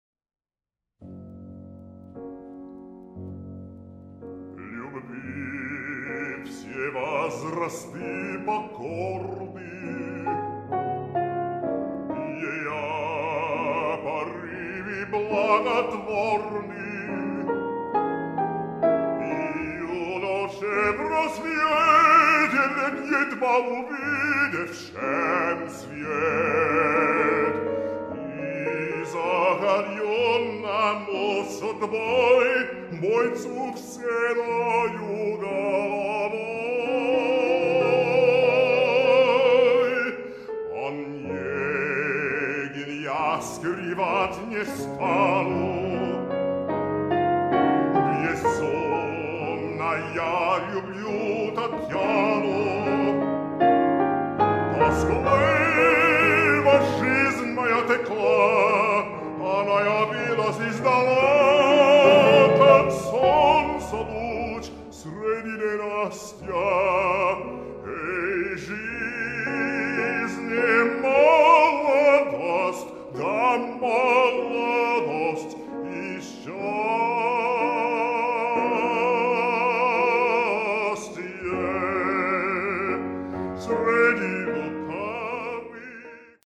Basso-Cantante